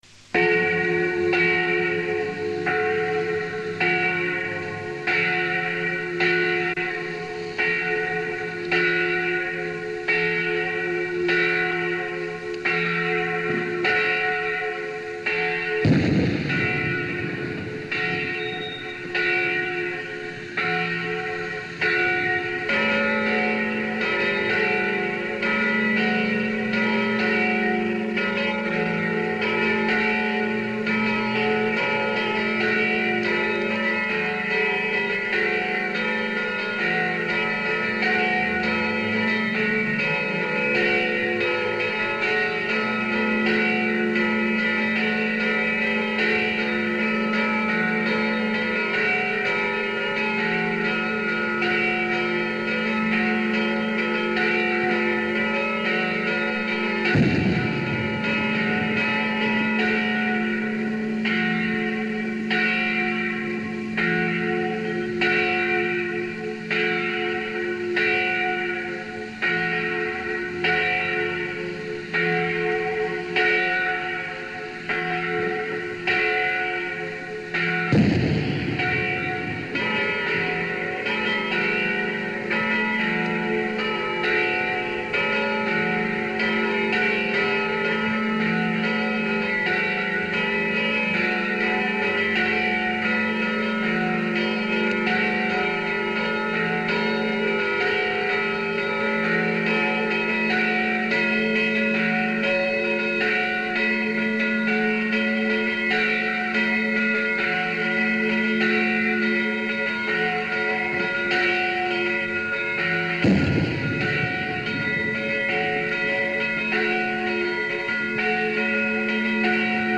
Ovviamente la registrazione è di tipo artigianale ed è realizzata con i mezzi disponibili all'epoca, ma ciò a mio avviso arricchisce ulteriormente il valore di testimonianza di ciò che ascolterete.
I SUONI DELLE CAMPANE DI SAN MICHELE
Festa di San Michele 1980
campane_di_san_michele_4.mp3